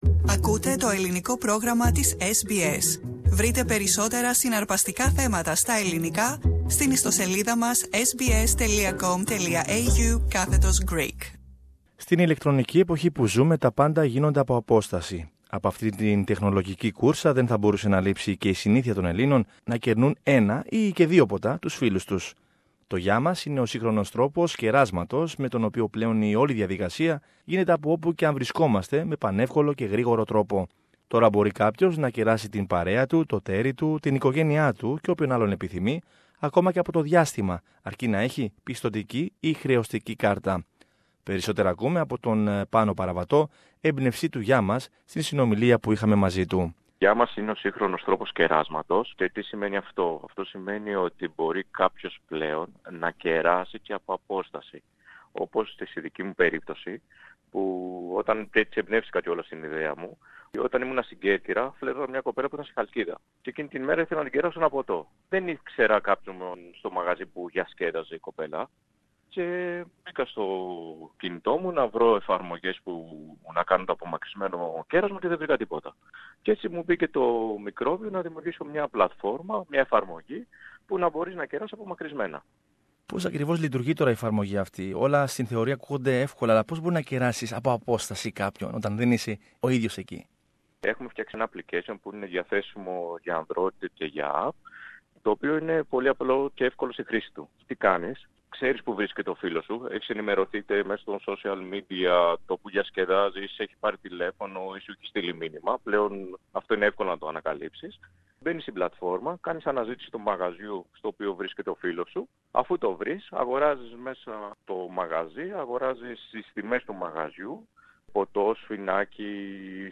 συνομιλία